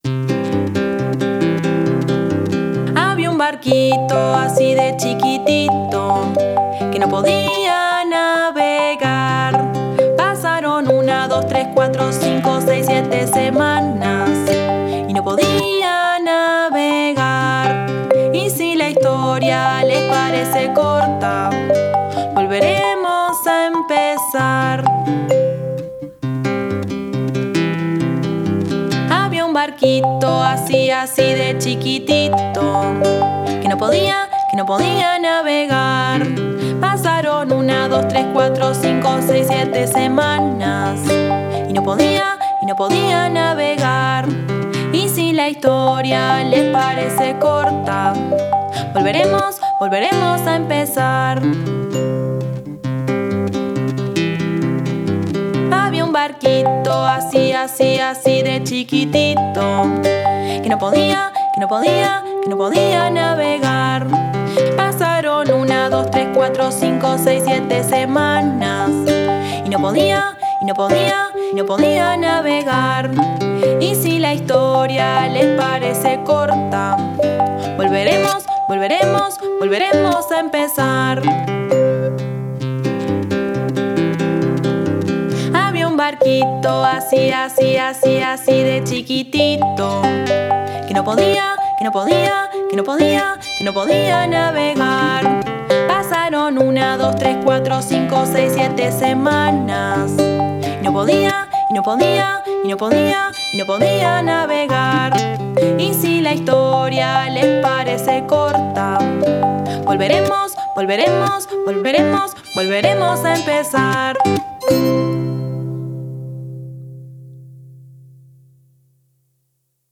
Retahila o canción de nunca acabar